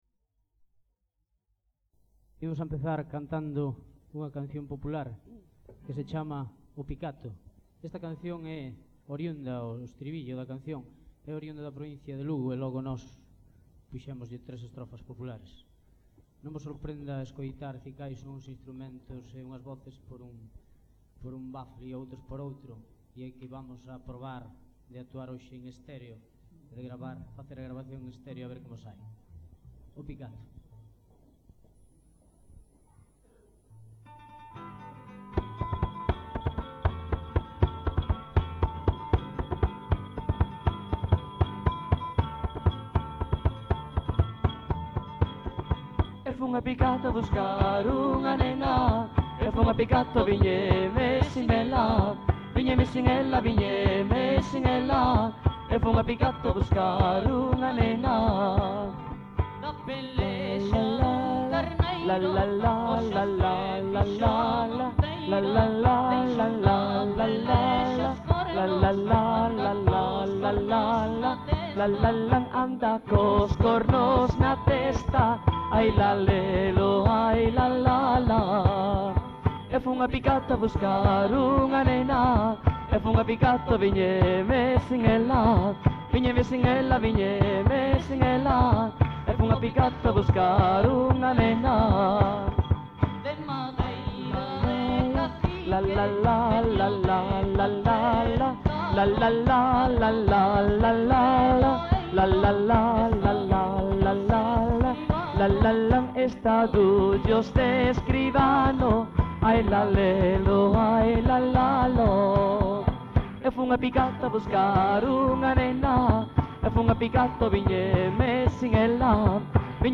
Letra: Popular
Música:Popular